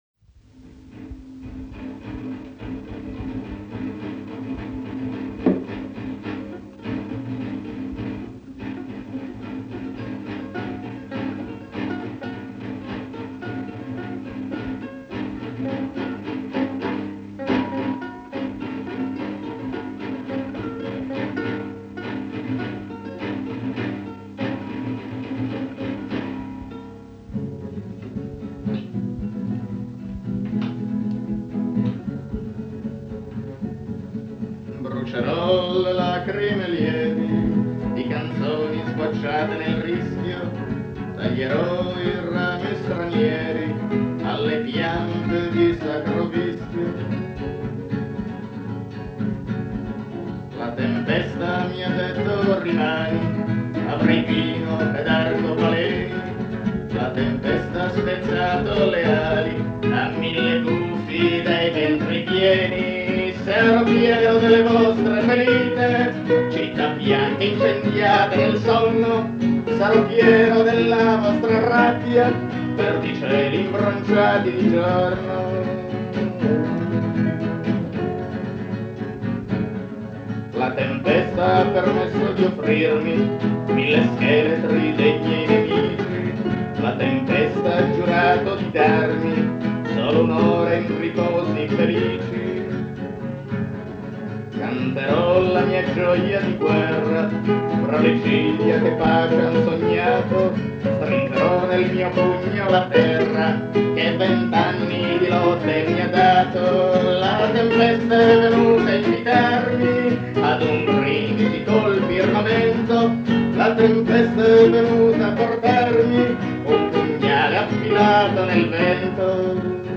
da una registrazione dei primi anni ’70